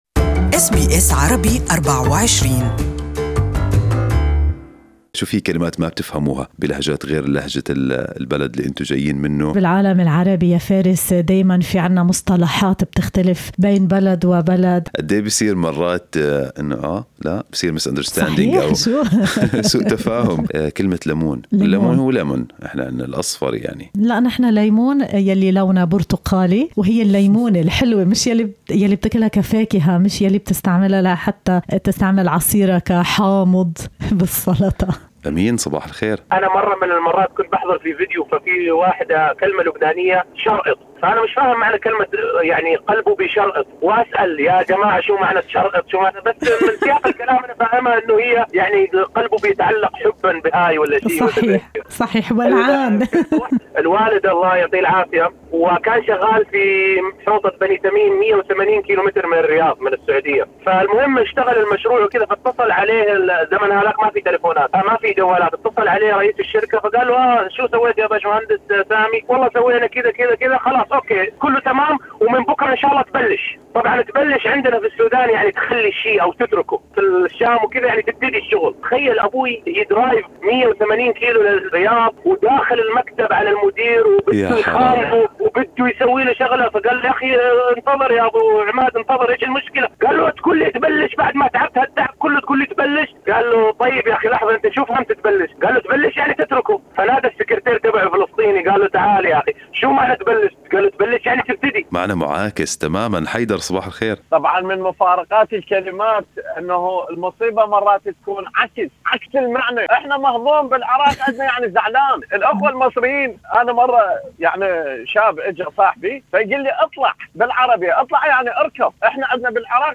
برنامج Good Morning Australia (يأتيكم من السادسة وحتى التاسعة صباحاً من الاثنين إلى الجمعة) طرح موضوع تعدد اللهجات العربية للحوار المباشر حيث شارك المستمعون بتجاربهم الشخصية وقصصهم الطريفة التي اكتشفوا خلالها أن نفس الكلمة قد تعني معانِ متضادة في دول عربية مختلفة.